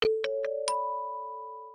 • Категория: Рингтон на смс